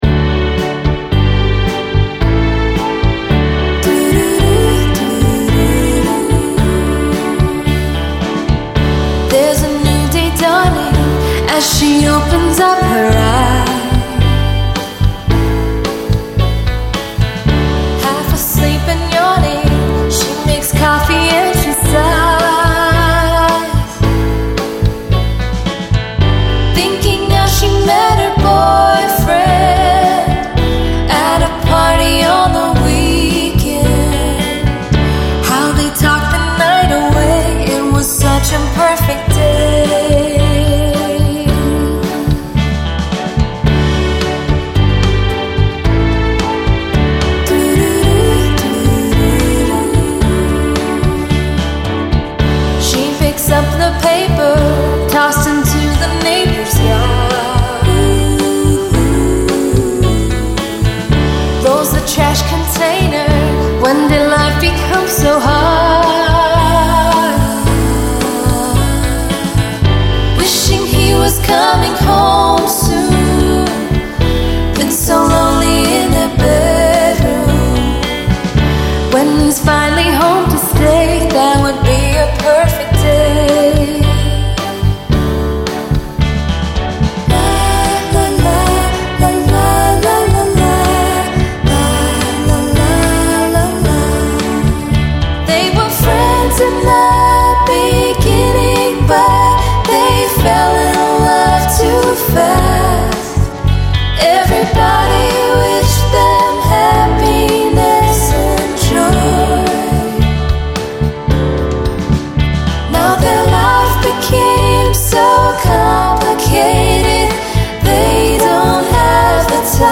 beautifully emotional popular music